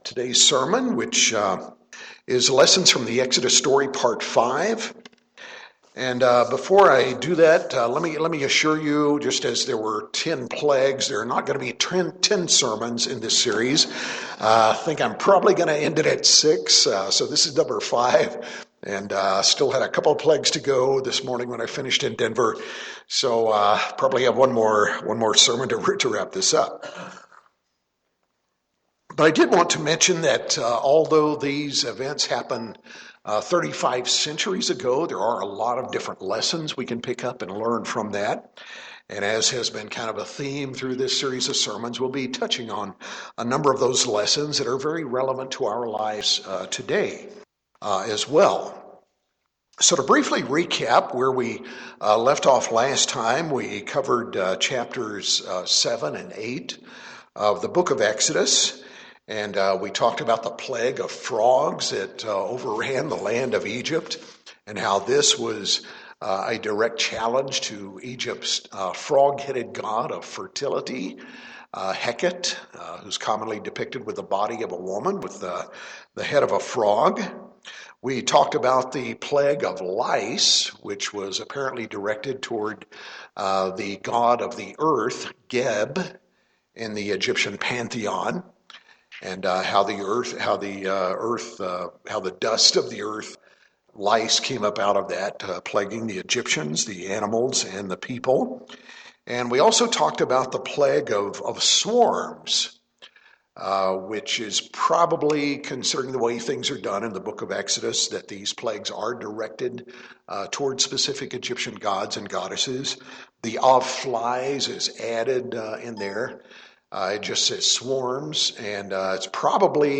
In part 5 of this series of sermons going into detail of the story of Israel ’ s Exodus from Egypt, we examine insights into the mind and character of the Egyptian Pharaoh as revealed in the biblical account, how Pharaoh is a type of Satan the devil, how national leaders can at times be their own nations’ worst enemies, the devastating economic impact of the plague on Egypt’s livestock, which Egyptian gods and goddesses were shown powerless in the face of the fifth through eight plagues, what it means when Pharaoh’s heart was hardened, the biblical clue that shows the plagues were spread out over a number of months, God’s instructions to the Israelites to remember these events, and what we gain (and lose) by remembering or failing to remember.